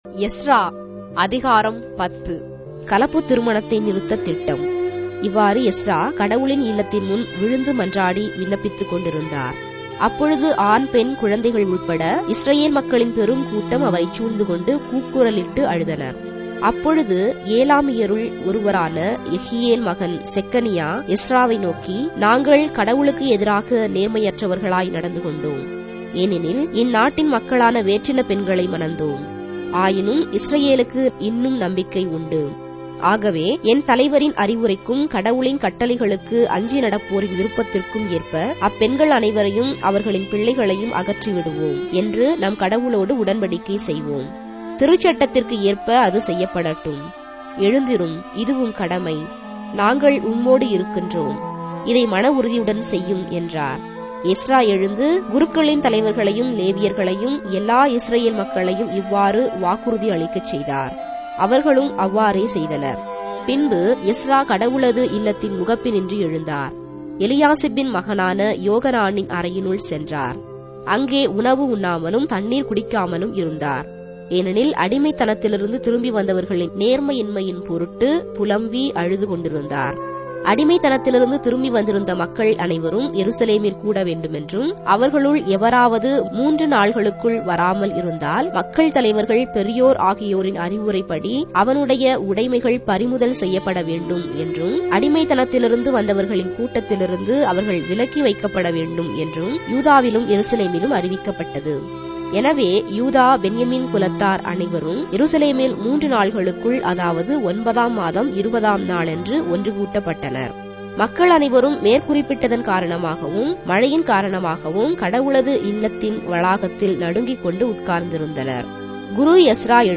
Tamil Audio Bible - Ezra 7 in Ecta bible version